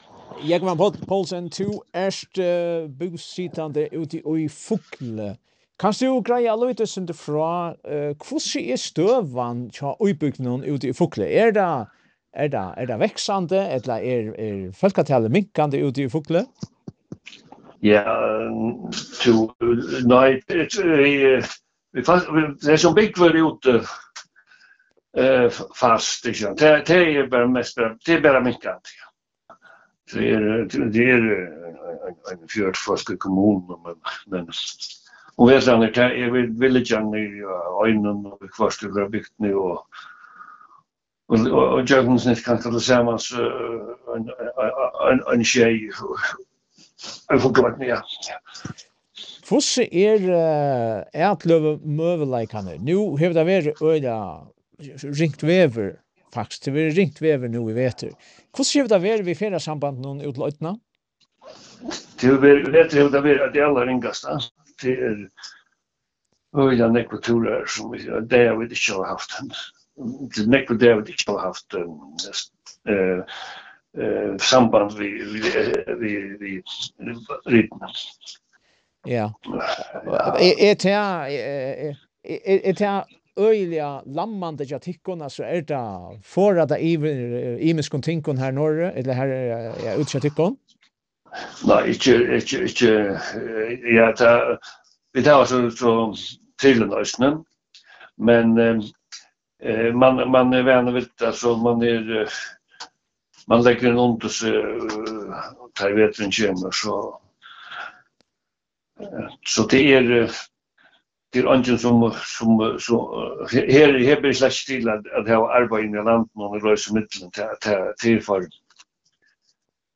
Samrøðan bleiv gjørd umvegis telefon.